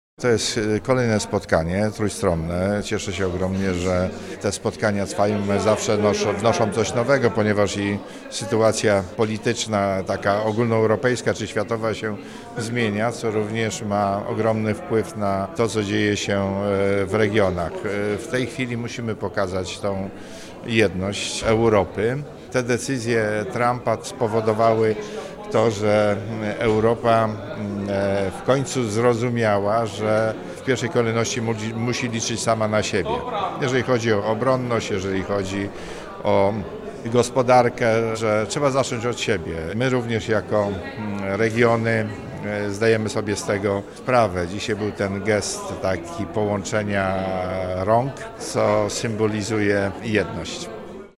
W sali Sejmiku Województwa Dolnośląskiego we Wrocławiu odbyło się trójstronne spotkanie prezydiów parlamentów regionalnych Dolnego Śląska, Autonomicznej Republiki Adżarii oraz Kraju Związkowego Brandenburgii.